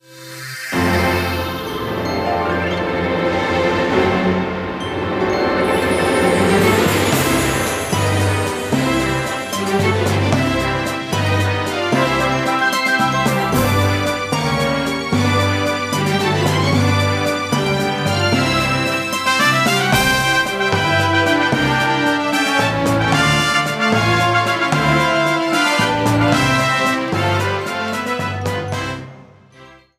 Fade-in and fade-out